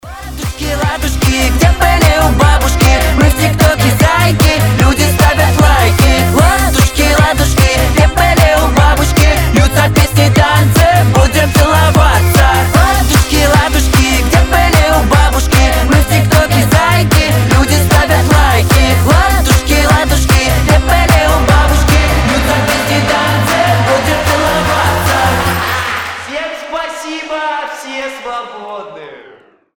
• Качество: 320, Stereo
детские